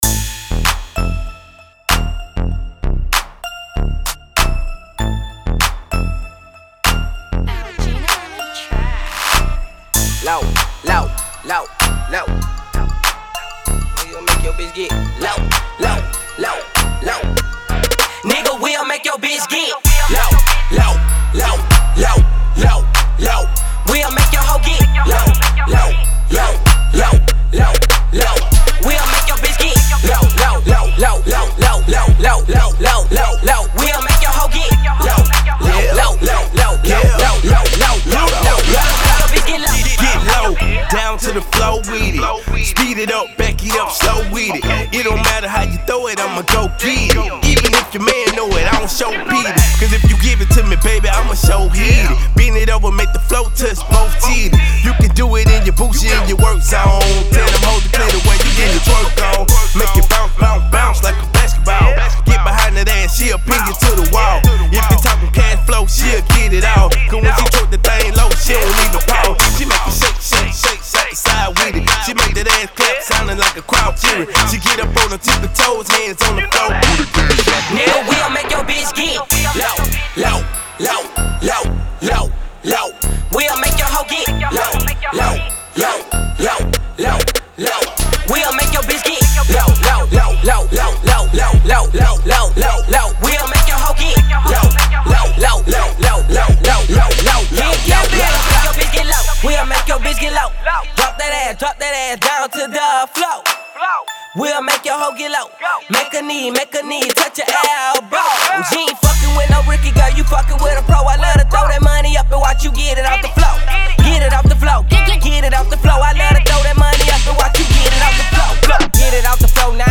Hiphop
an up tempo party track that has popular melodic tones